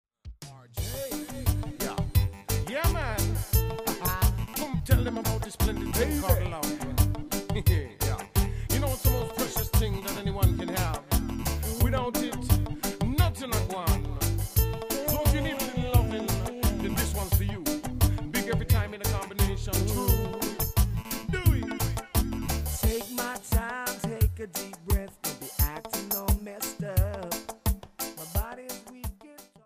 Genre: Island reggae.